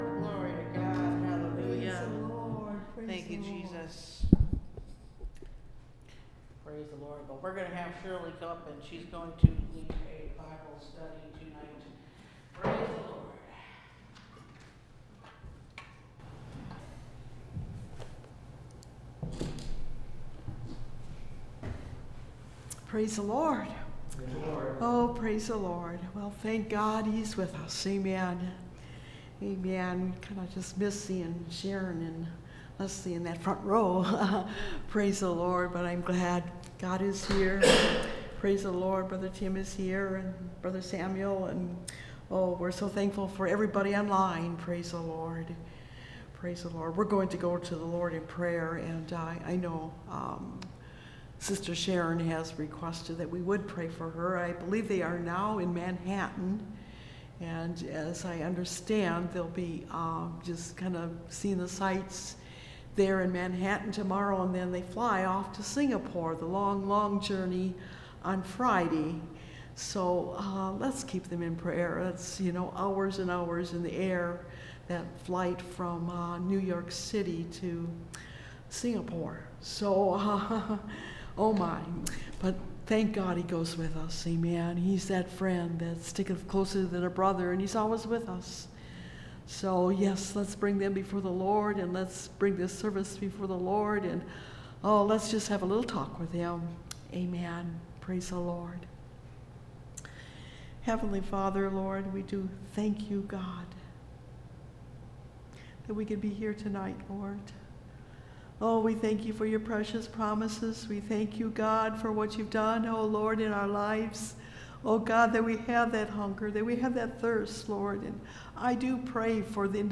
A Covering For Sin – Part 1 (Message Audio) – Last Trumpet Ministries – Truth Tabernacle – Sermon Library